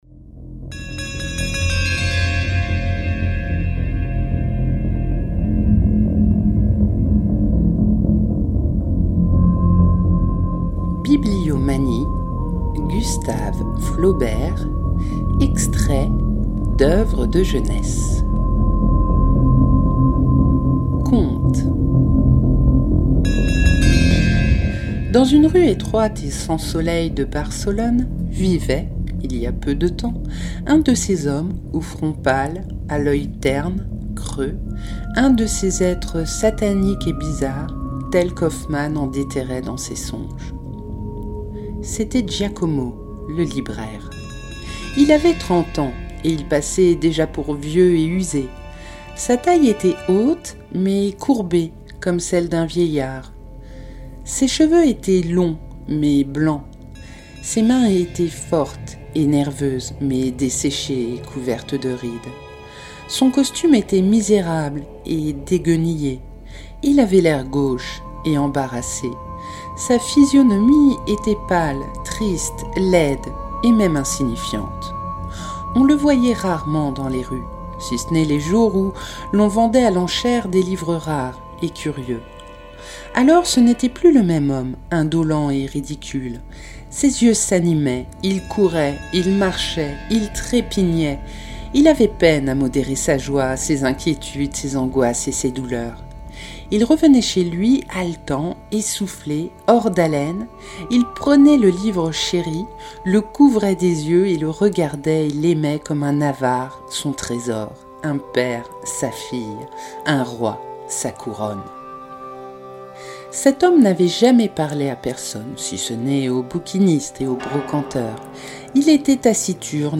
Conte (30:49)